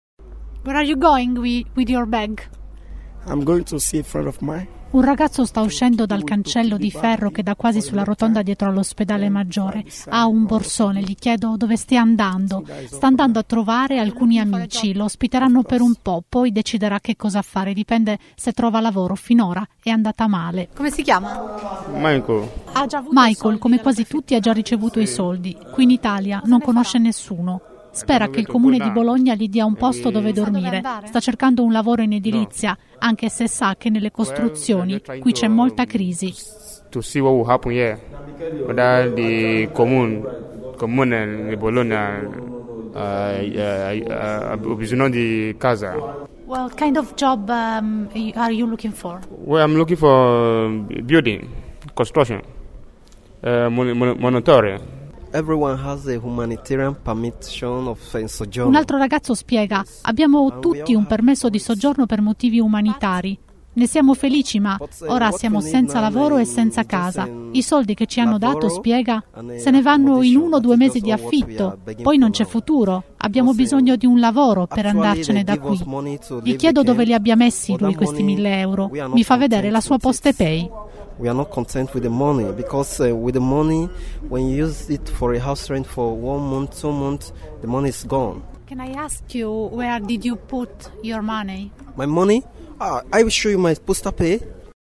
voci-profughi-sito.mp3